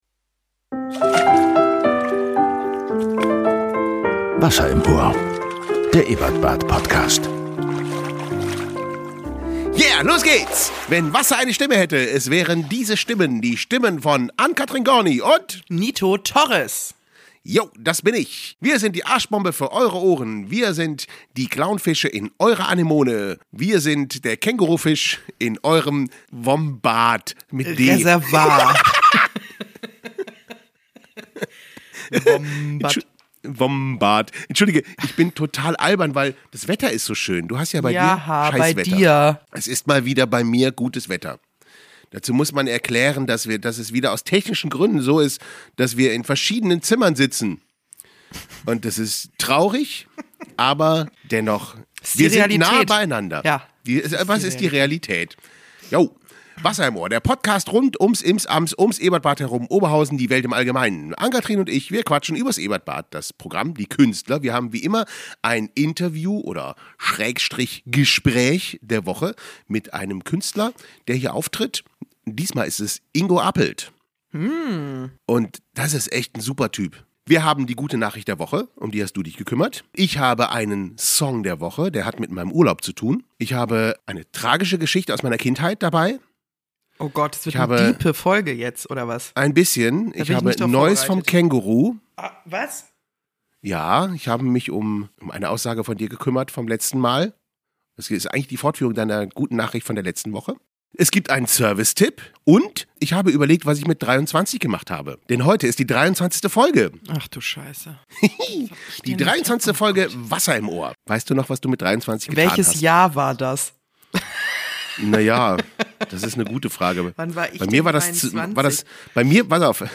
Im Interview: Ingo Appelt Die Sonne scheint in Bottrop.